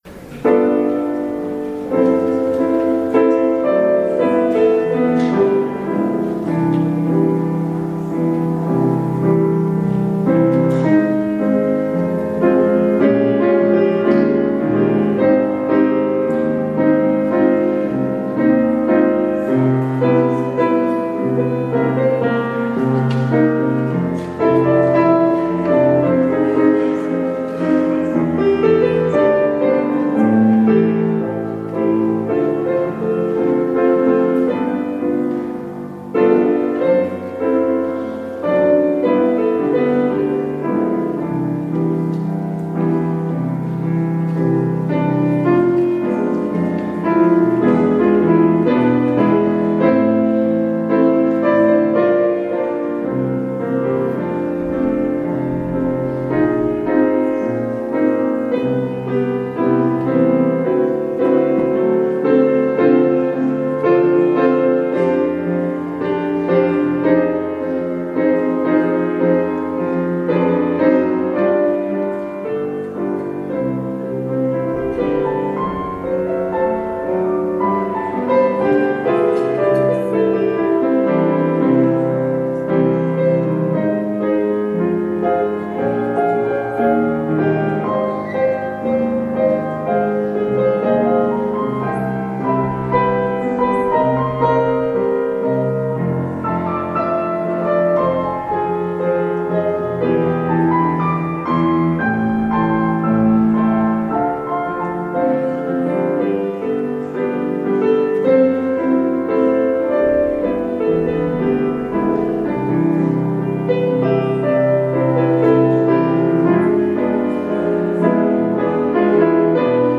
Audio recording of the 10am hybrid/streamed service, sermon
Opening Hymn
Communion music